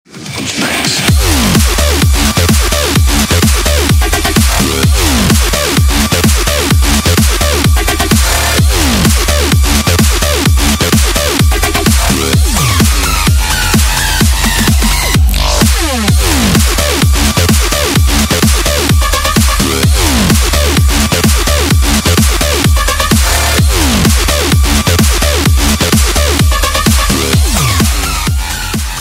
Клубные Рингтоны » # Громкие Рингтоны С Басами
Рингтоны Электроника